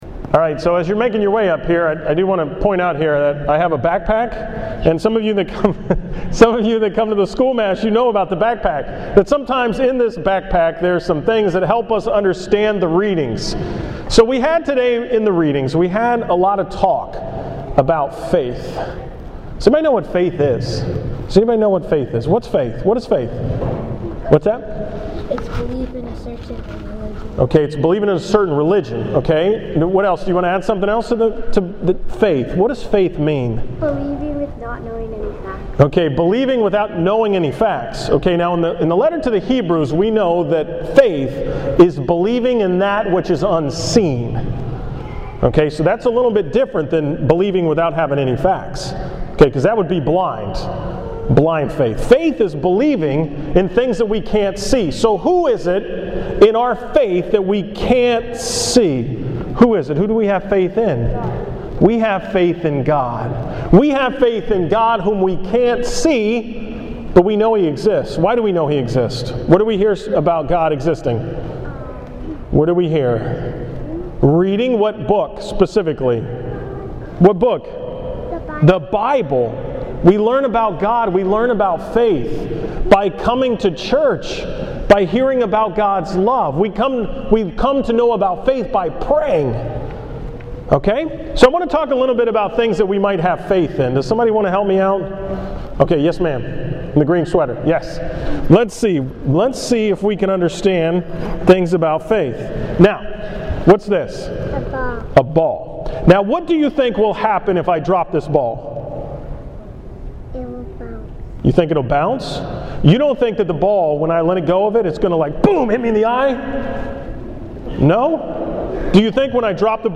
From Sunday October 6, 2013 at the 11 am Mass